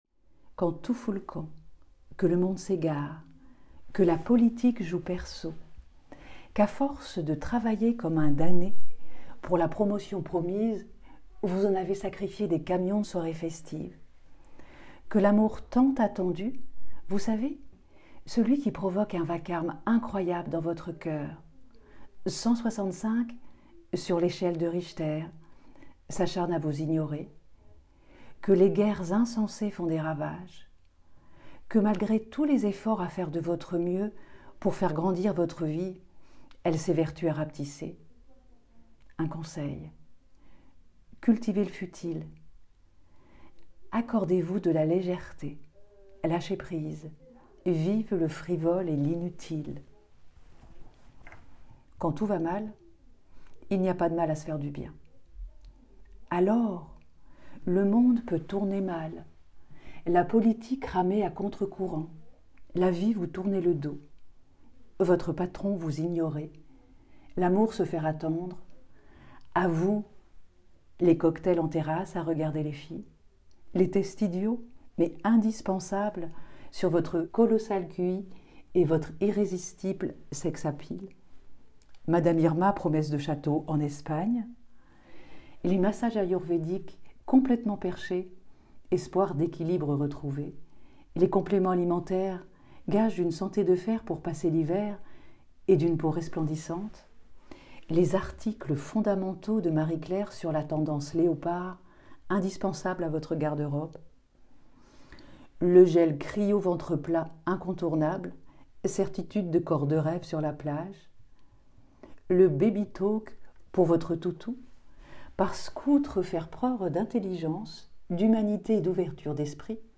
7 Fév 2025 | Article audio